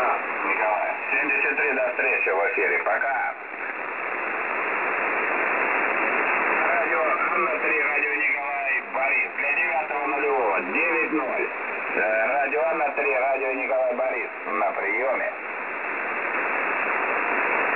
Запись эфира на мини вип.
websdr_recording_201.wav